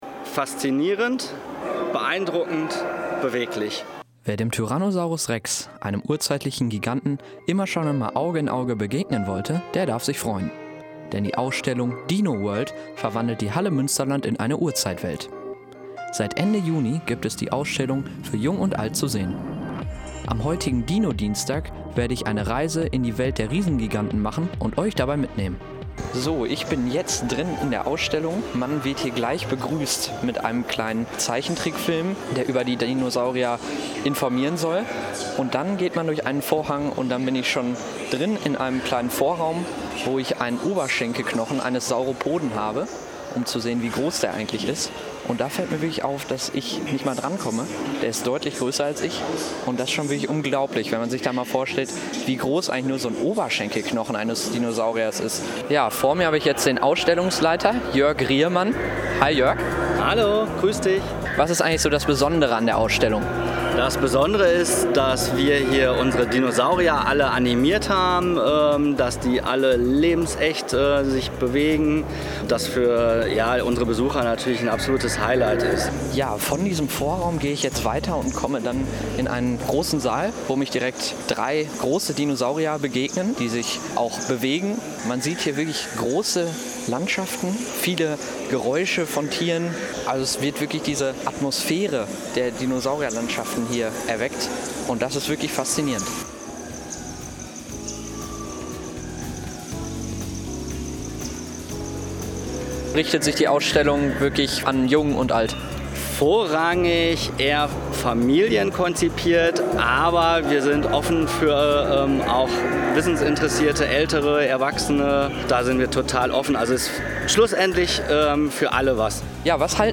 Verwendete Musiktitel: